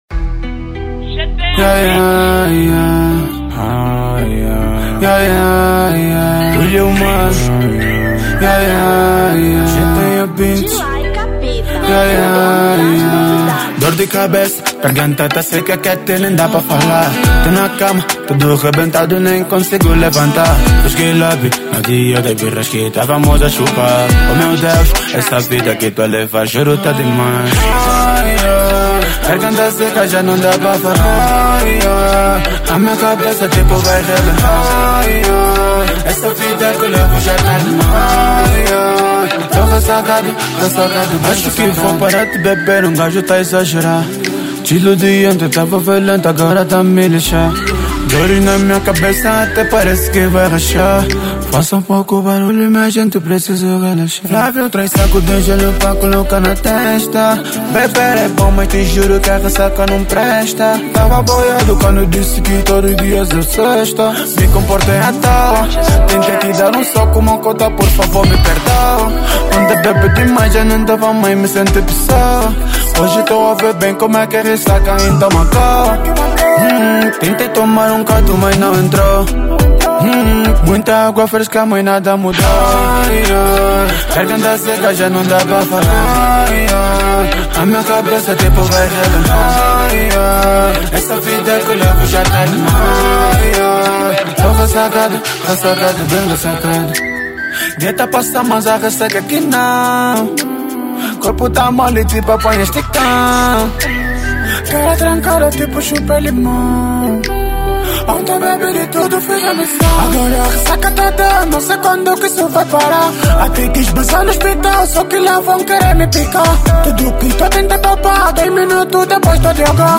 Drill 2022